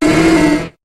Cri de Maraiste dans Pokémon HOME.